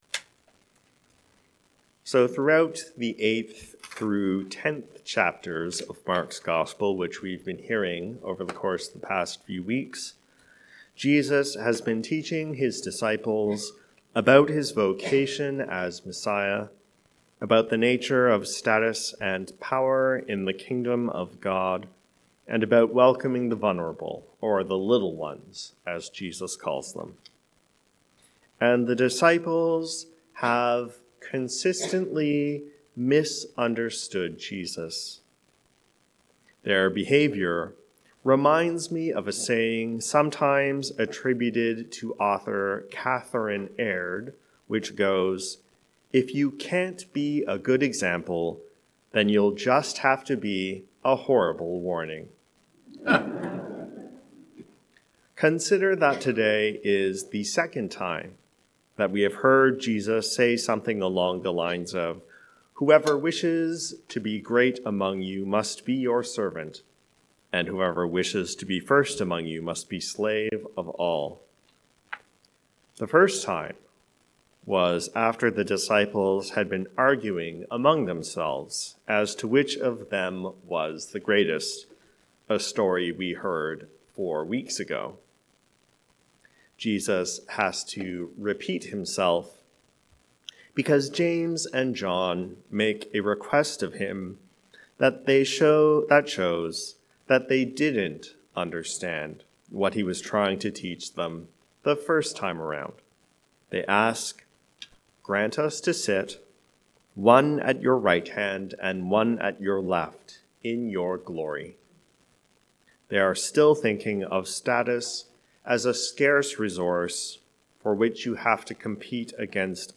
A Good Example or a Clear Warning? A sermon on Mark 10:32-45